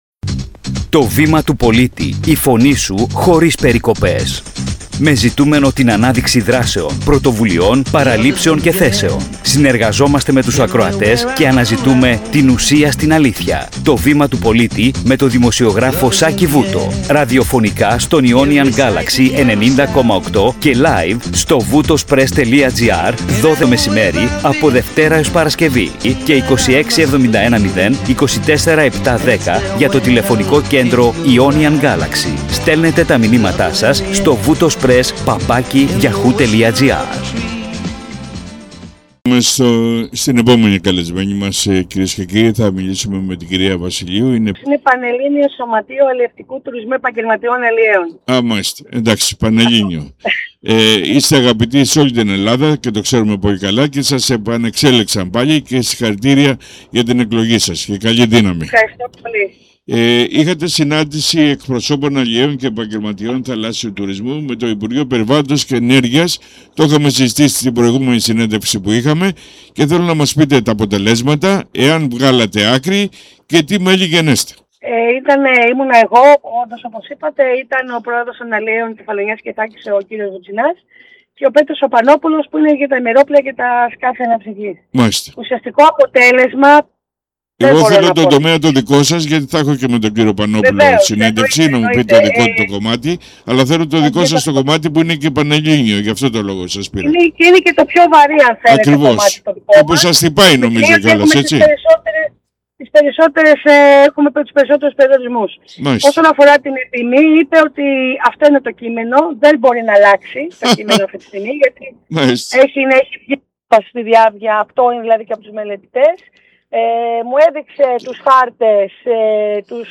Συνέντευξη
στον ραδιοφωνικό σταθμό Ionian Galaxy 90.8